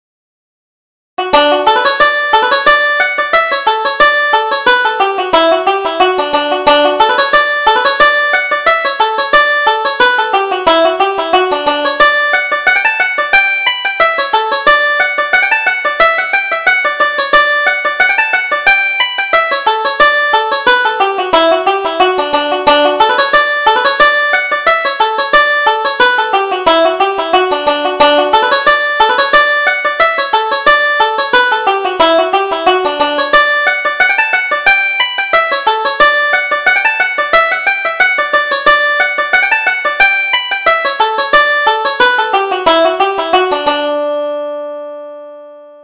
Reel: Courting Them All